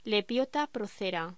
Locución: Lepiota procera
voz